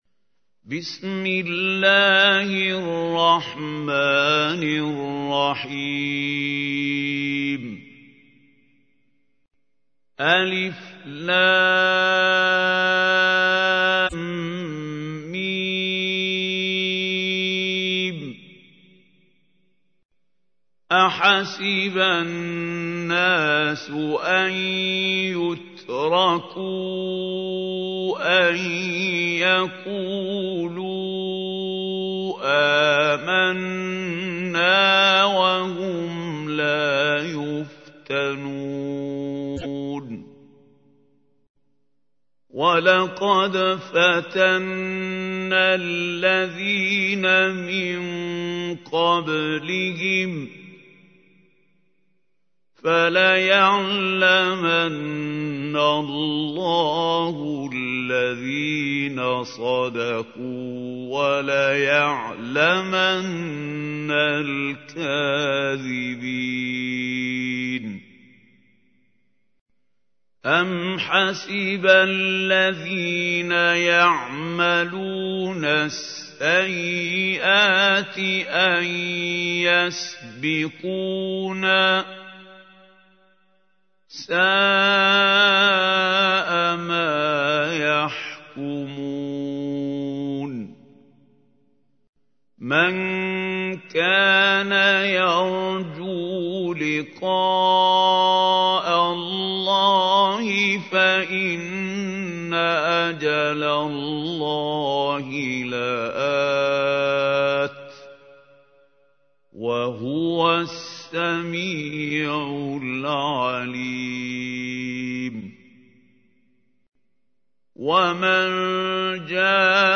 تحميل : 29. سورة العنكبوت / القارئ محمود خليل الحصري / القرآن الكريم / موقع يا حسين